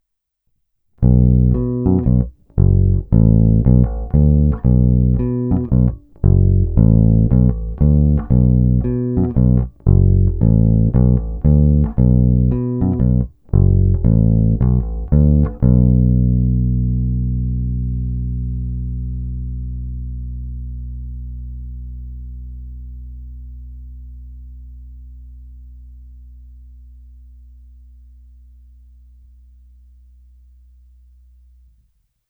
Zvuk je tučný, na jeho pozadí i přes tupé struny slyším zvonivé vrčení typické právě pro modely 1957-1959.
Není-li řečeno jinak, následující nahrávky jsou provedeny rovnou do zvukové karty a kromě normalizace ponechány bez úprav. Tónová clona vždy plně otevřená.
Hra nad snímačem